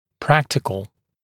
[‘præktɪkl][‘прэктикл]практический